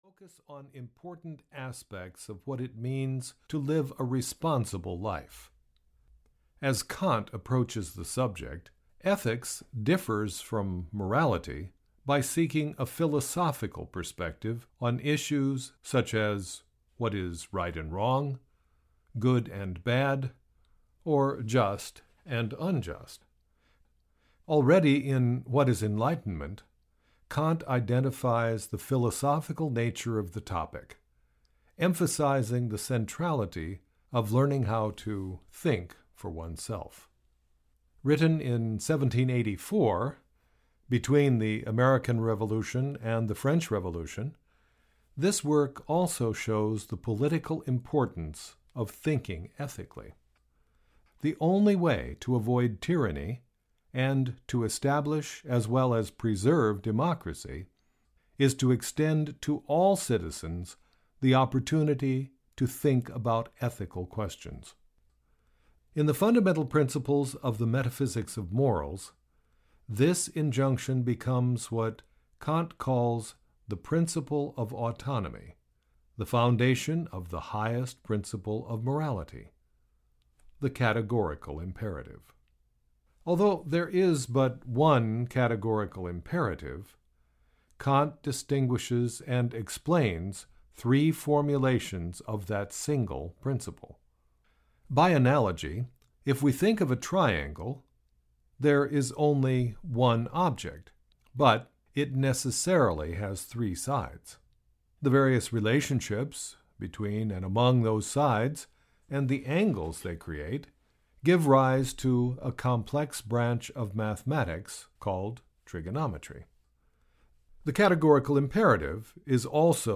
Kant’s Foundations of Ethics (EN) audiokniha
Ukázka z knihy